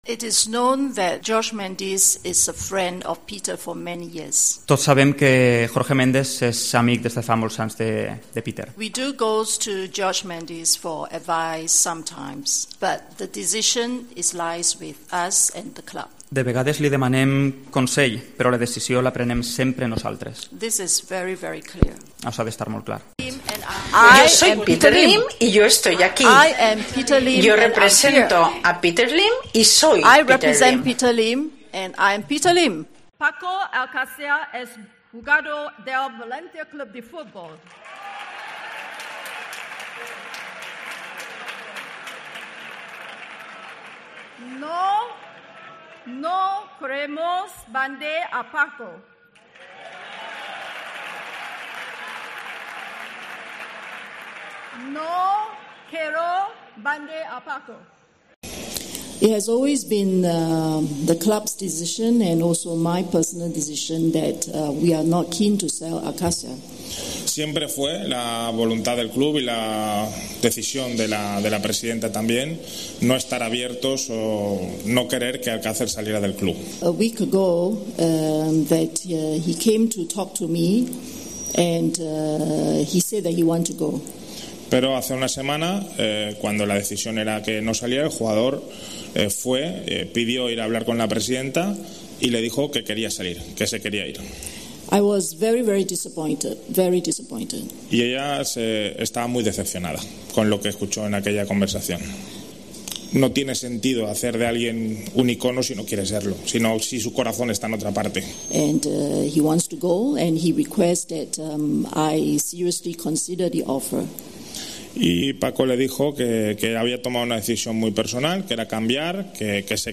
Lay Hoon en rueda de prensa
Sus comparecencias eran en inglés, con diferentes intérptetes al lo largo de los años, aunque asistía a clases particulares de español y se atrevió a expresarse en nuestro idioma alguna vez.